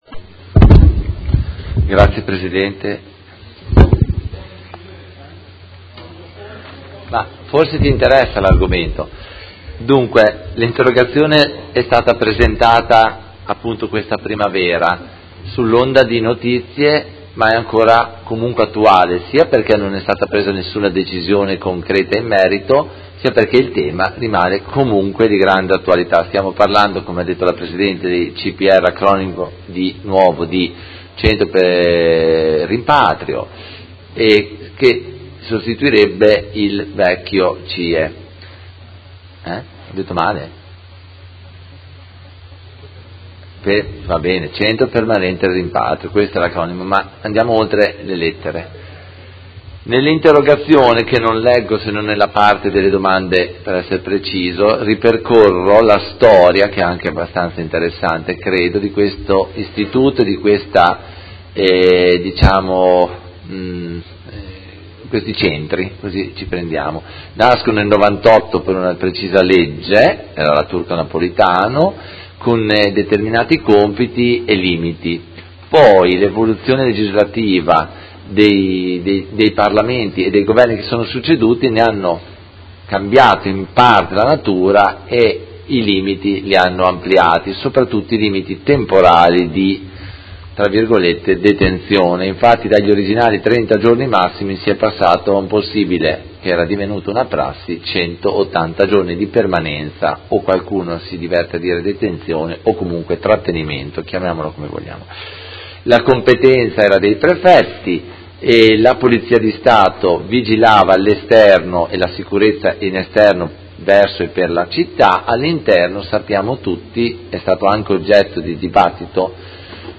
Seduta del 12/10/2017 Interrogazione del Consigliere Carpentieri (PD) avente per oggetto: Apertura CPR in città